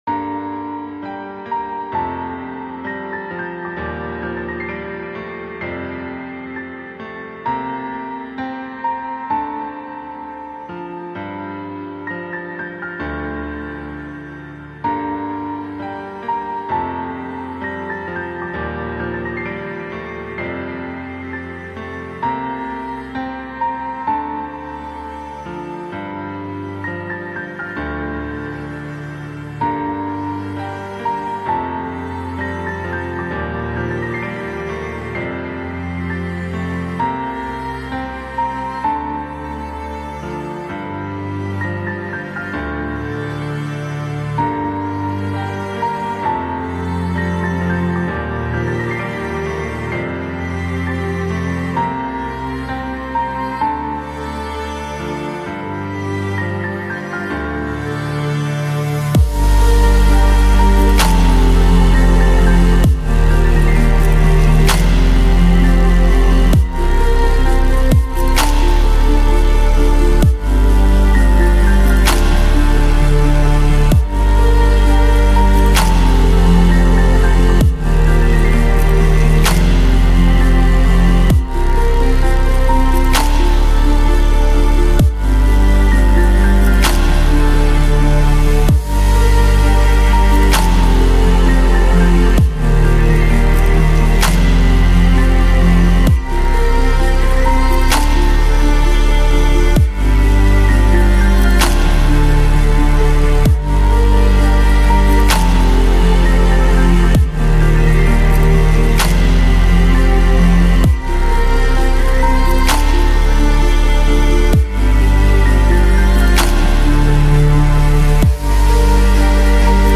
это нежная и мелодичная песня в жанре инди-поп